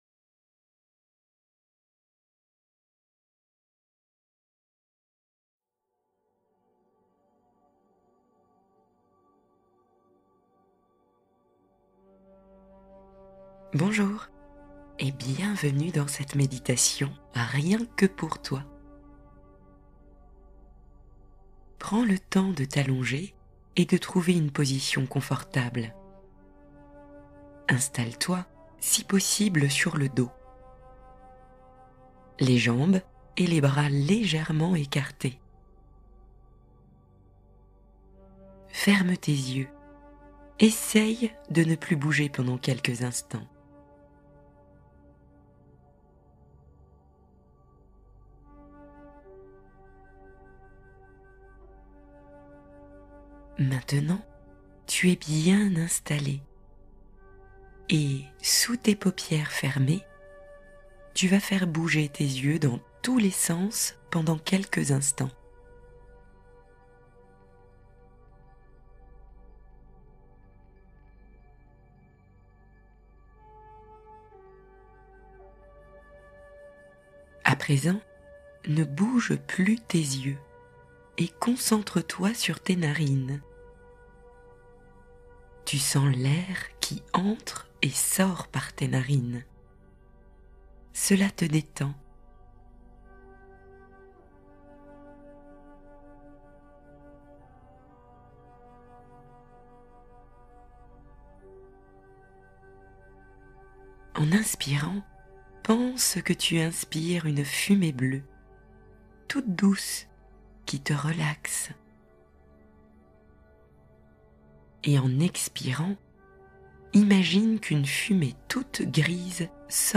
Guérison par les cinq éléments : relaxation guidée et équilibre